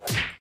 HardPunch.ogg